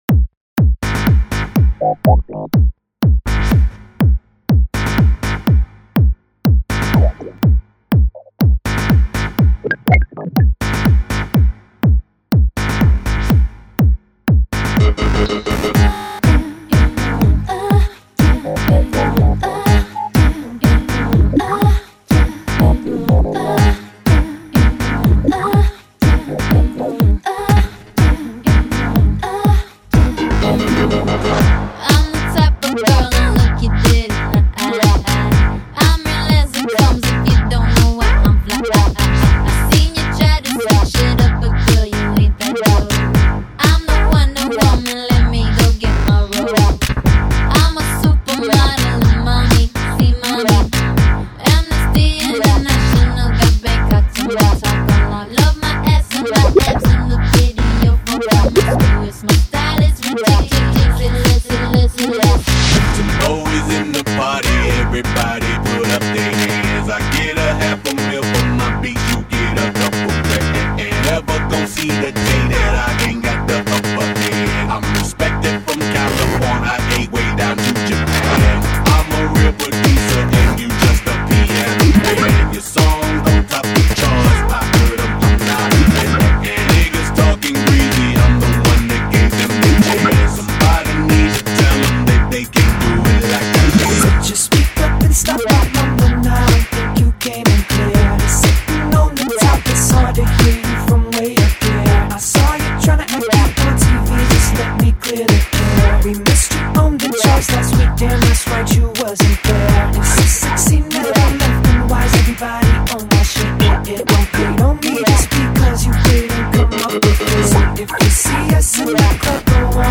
Ремикс на некогда популярный трэк в стиле электро хаус.